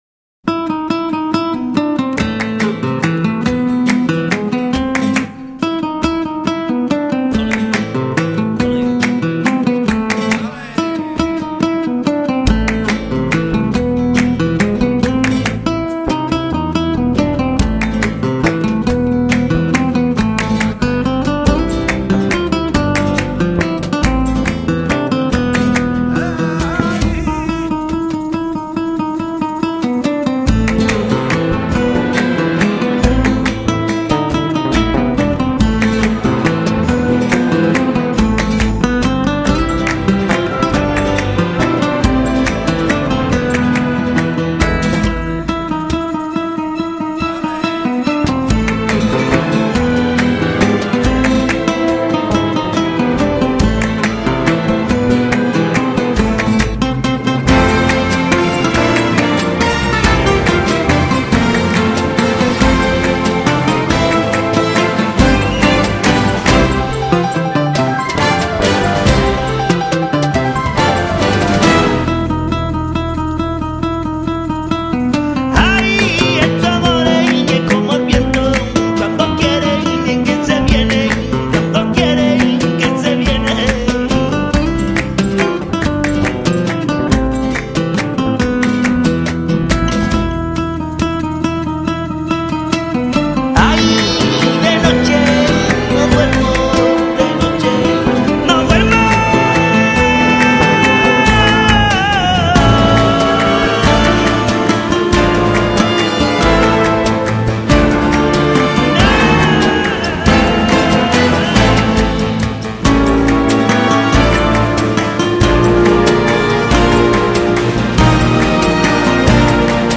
:) Еще и поют!!! подпевают...